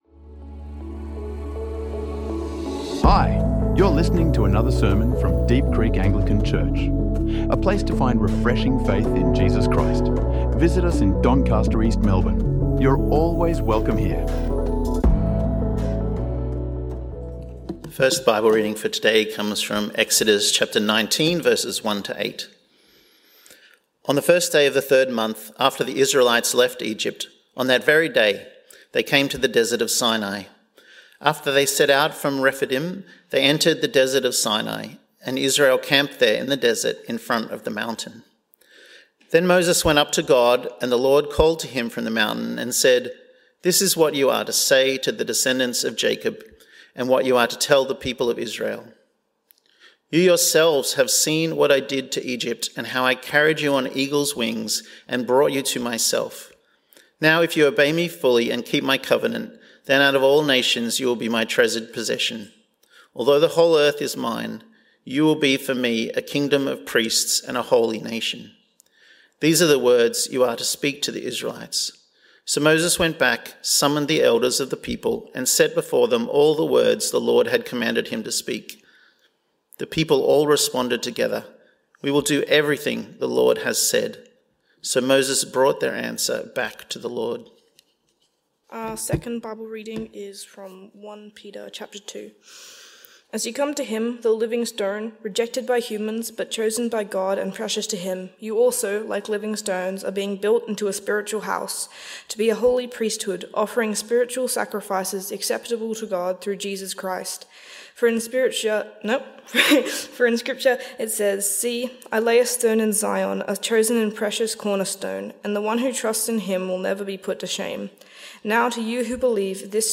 The Identity of the Church from the Book of Exodus - Week 2 | Sermons | Deep Creek Anglican Church